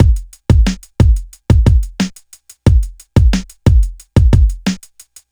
• 90 Bpm Breakbeat Sample G Key.wav
Free drum groove - kick tuned to the G note. Loudest frequency: 703Hz
90-bpm-breakbeat-sample-g-key-cbN.wav